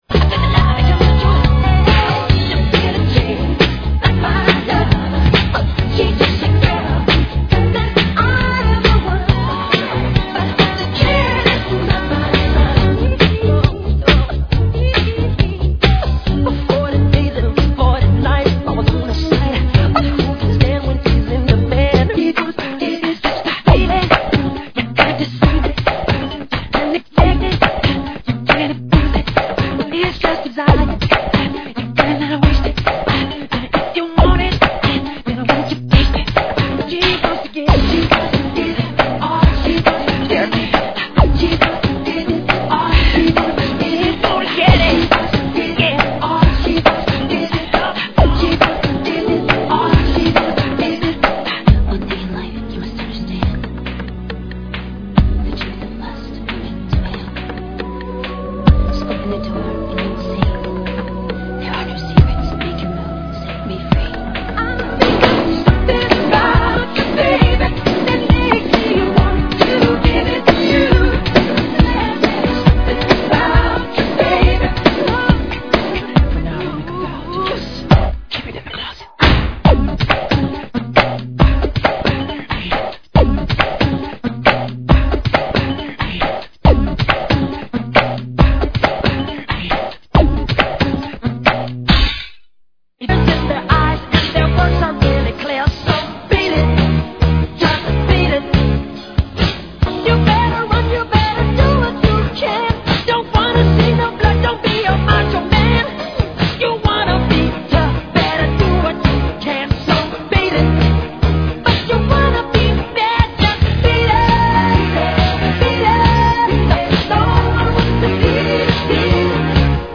AEROBICS (STEP-HILOW)